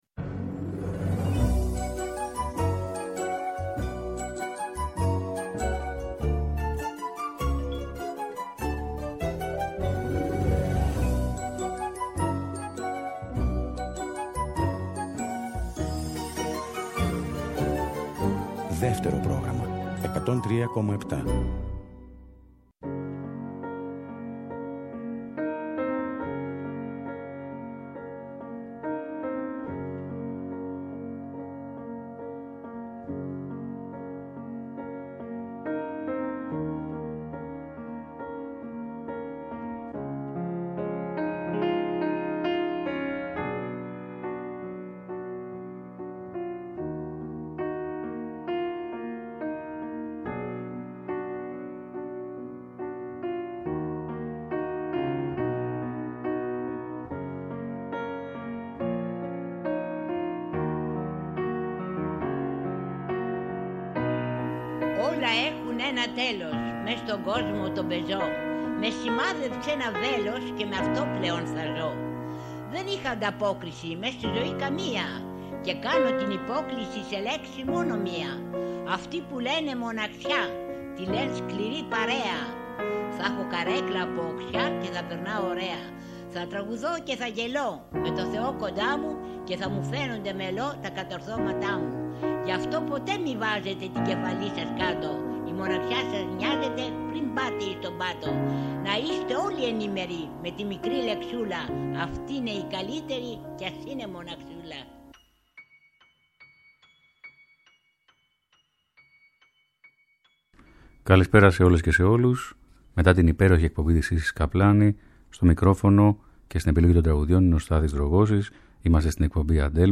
Με ευαισθησία και μυστικισμό, με ερωτισμό και κυνισμό οι ροκ μπαλάντες αγαπήθηκαν πολύ από το ελληνικό κοινό! Τραγούδια ροκ ερωτικά ευαίσθητα και ελληνικά αυτό το Σάββατο 26 Αυγούστου στην Αντέλμα στις 5 το απόγευμα ακριβώς!